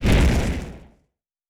Fire Explosion 1.wav